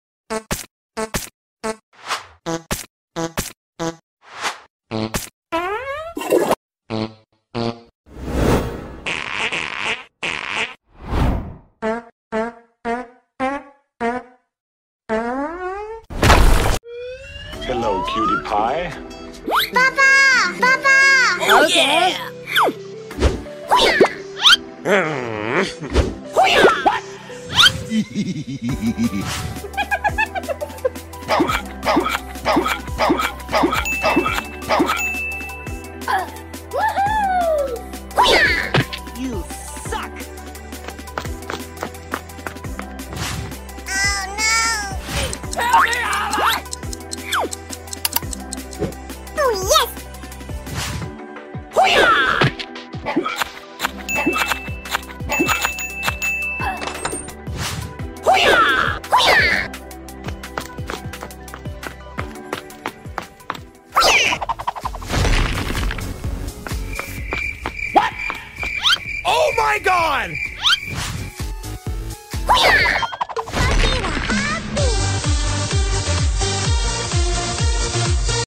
Funny animated cartoon video spider sound effects free download